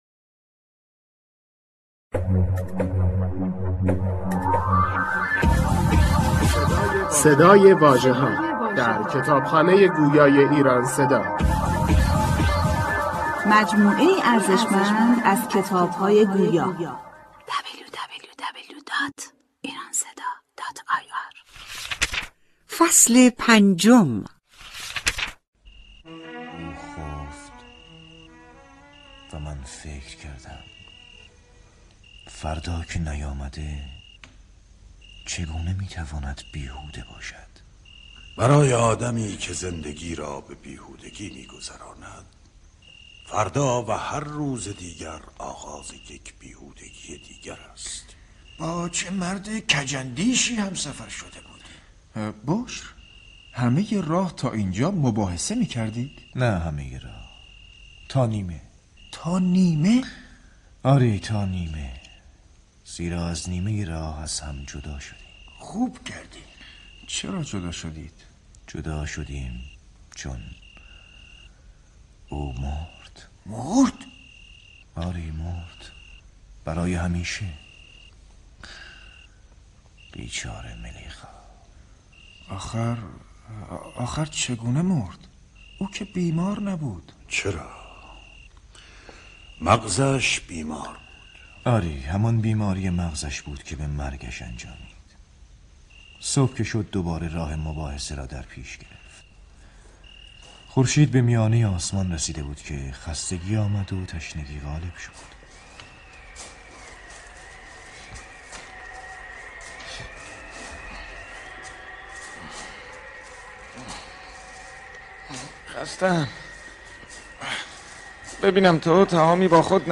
اثری نمایشی با صدای بازیگران برجسته و فضاسازی عرفانی.